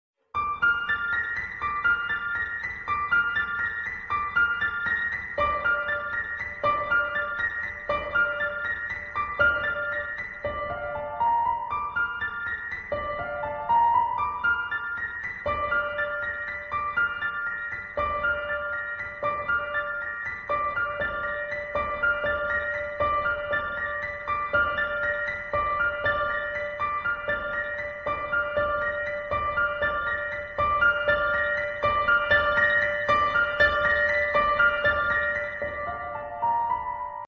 Classic Horror 2 (Audio Only) sound effects free download